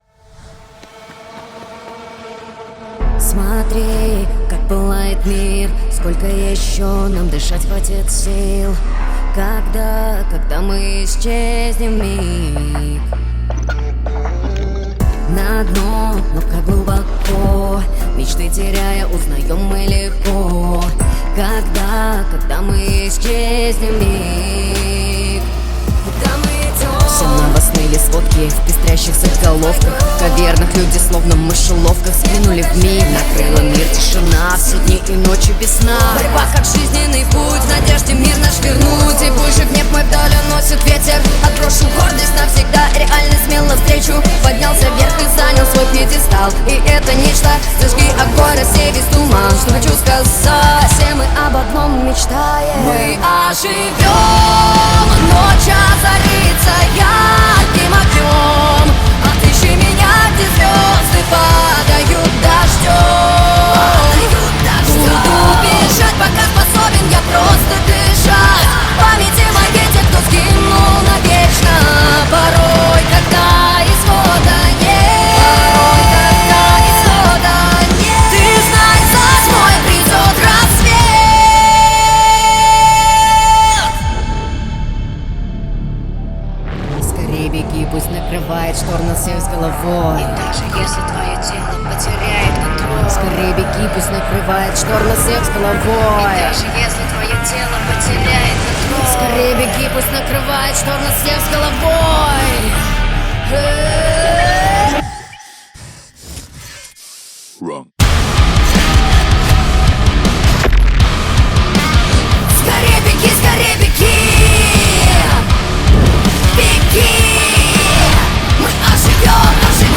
кавер на русском